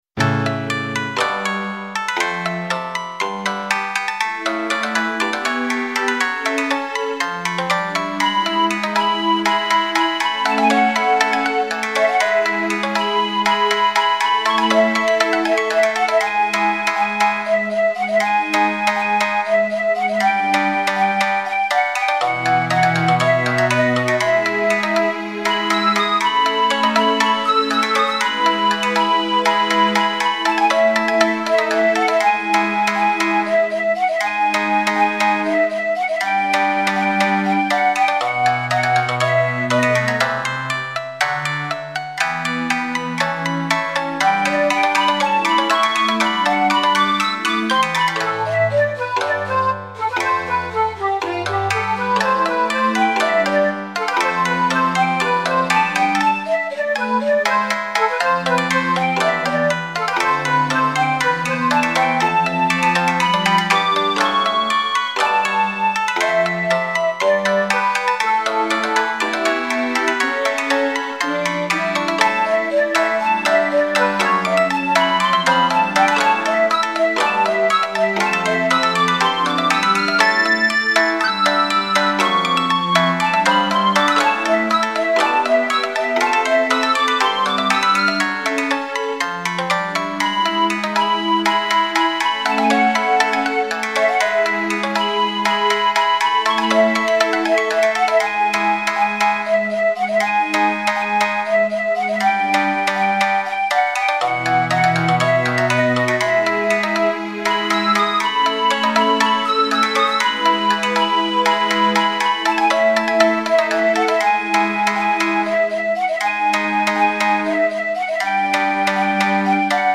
Japanese Music Style
おしゃべりな感じ。三味線×２、尺八、スローストリングスによる和風アレンジ。 フェードアウトします。